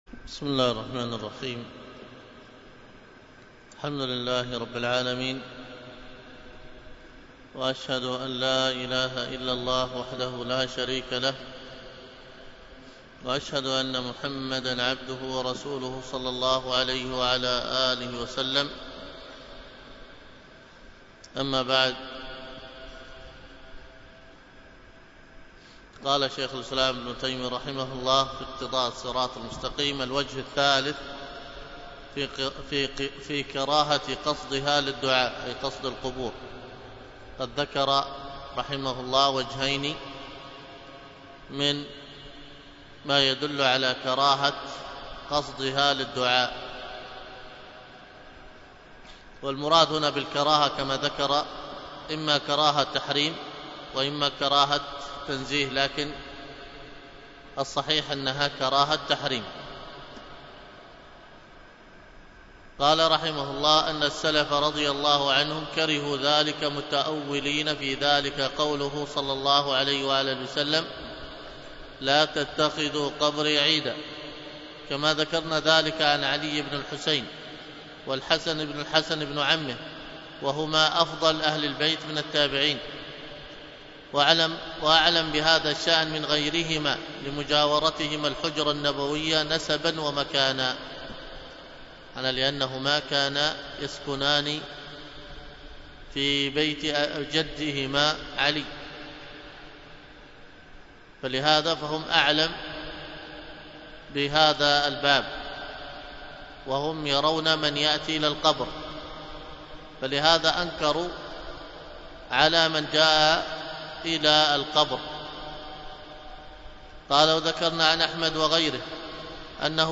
التعليق على اقتضاء الصراط المستقيم لمخالفة أصحاب الجحيم | الدروس